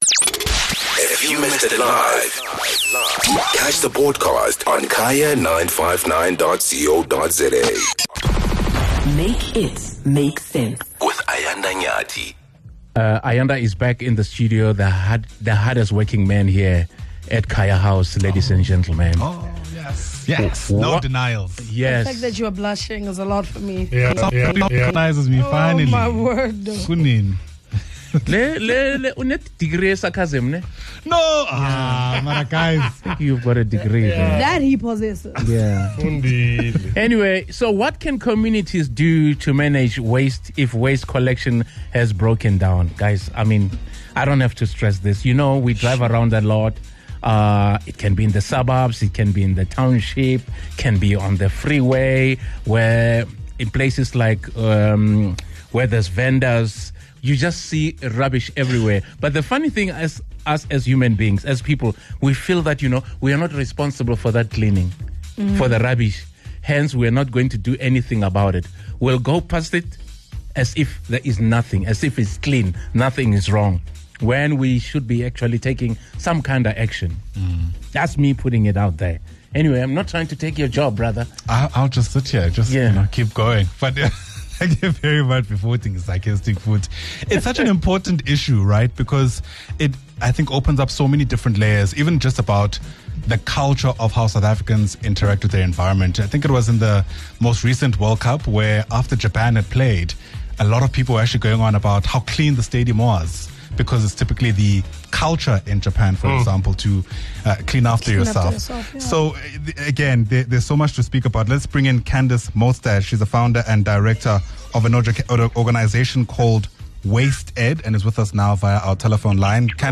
Listeners also shared the impact that inefficient waste collection has had on their communities.